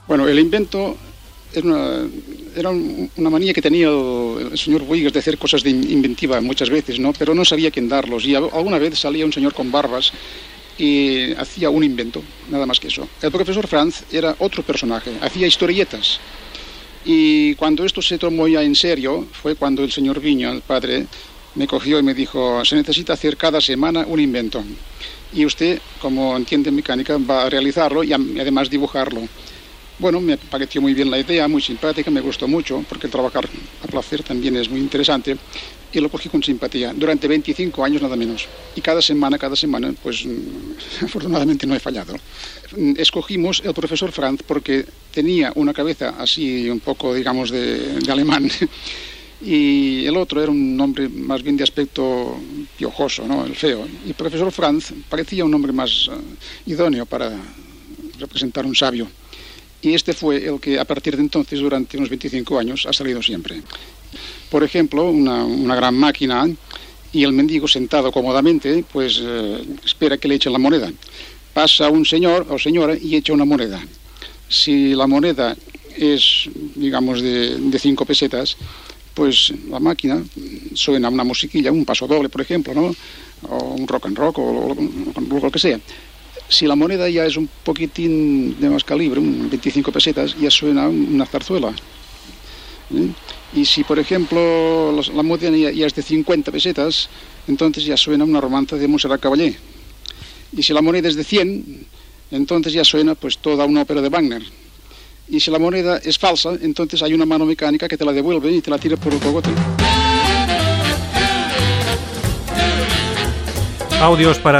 Fragment extret del programa "Audios para recordar" de Radio 5 emès el 20 de novembre del 2017.